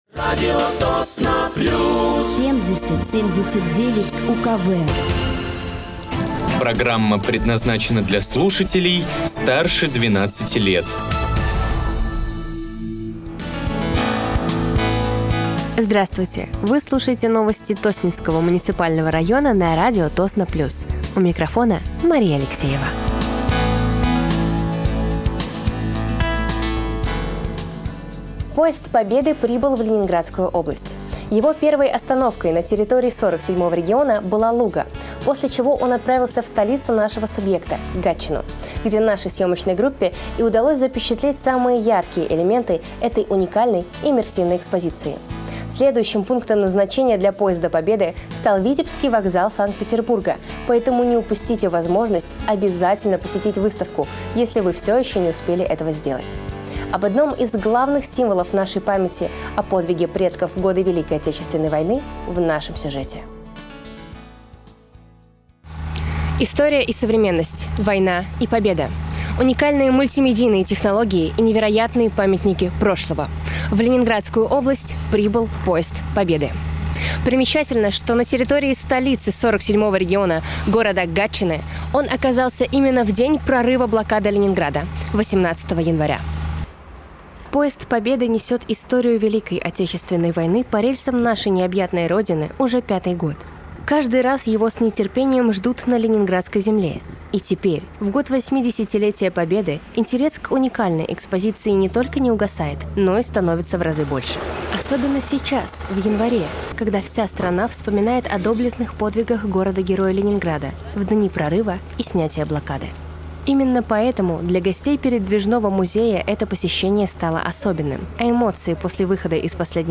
Вы слушаете новости Тосненского муниципального района на радиоканале «Радио Тосно плюс». Сегодня в выпуске: Поезд Победы прибыл в Гатчину; Афиша памятных мероприятий в Тосненском районе ко Дню полного снятия блокады Ленинграда; Правила оплаты за проезд в общественном транспорте; Коммунальные службы Тосненского района очищают дороги и борются с гололедицей; Пожилым людям и инвалидам будут помогать волонтеры …